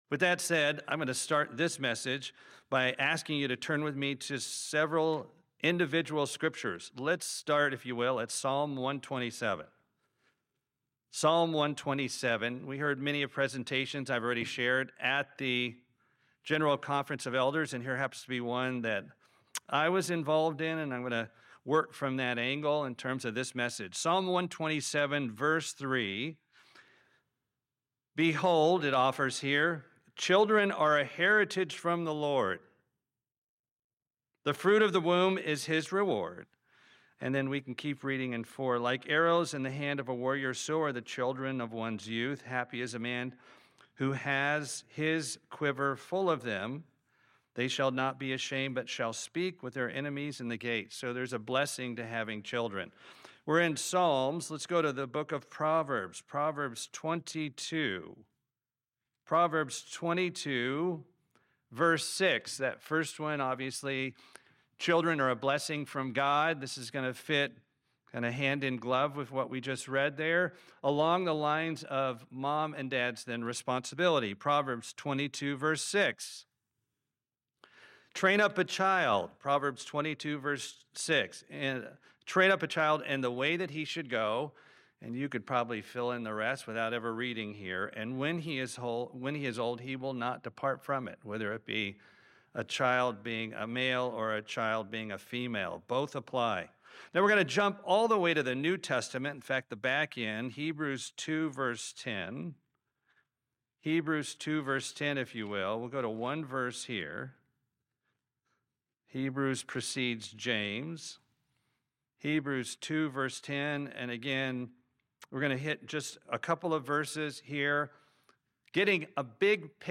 Sermon
Given in Atlanta, GA Buford, GA